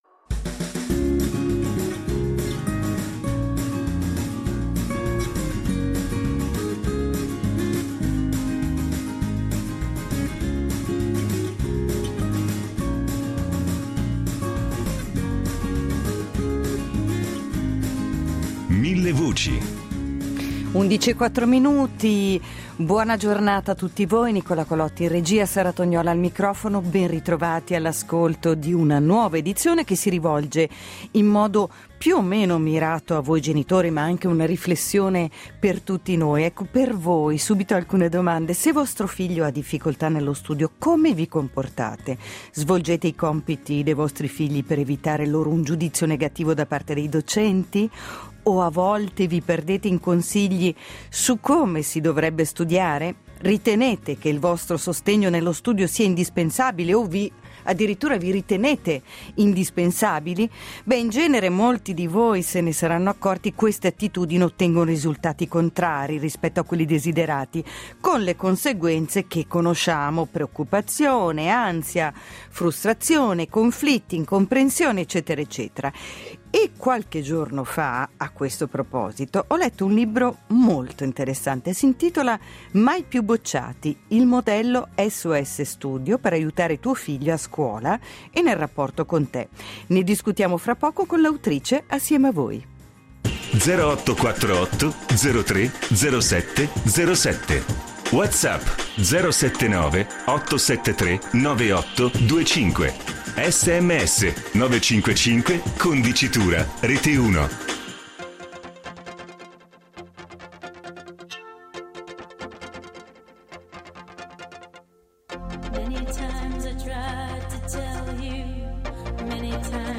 psicologa e psicoterapeuta Scopri la serie Millevoci https